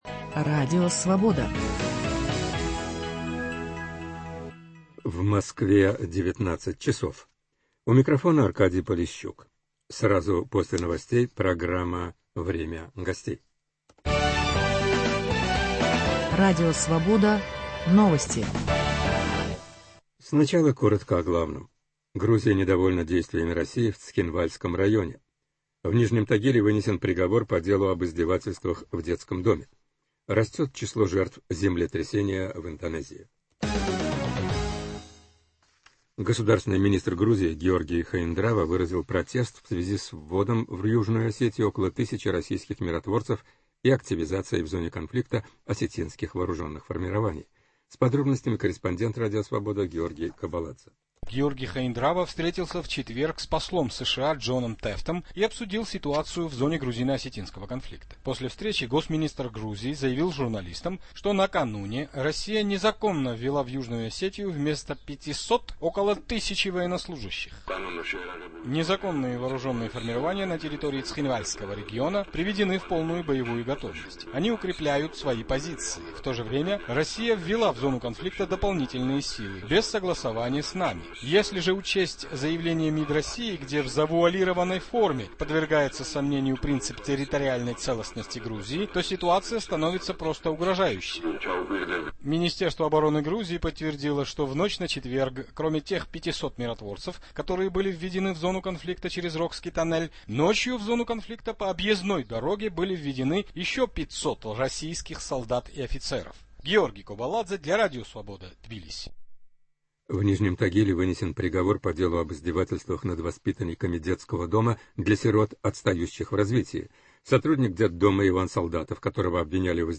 В Московской студии Радио Свобода